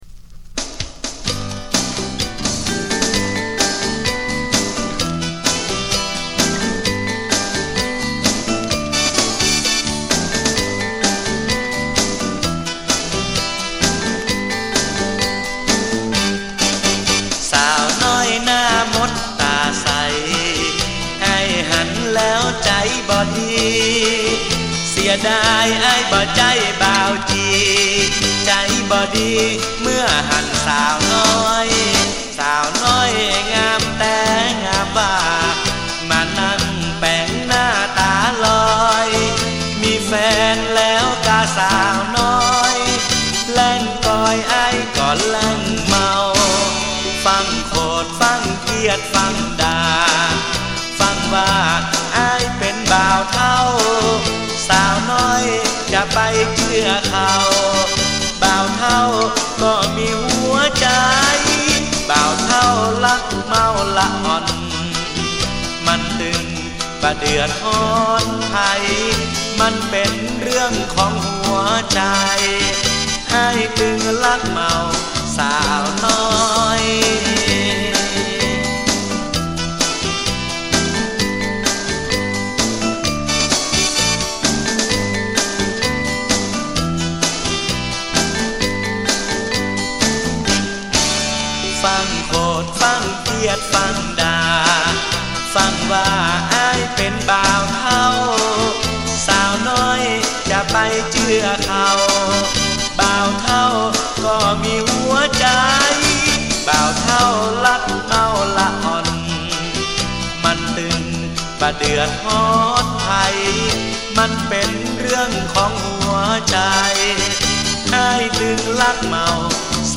เพลงคำเมือง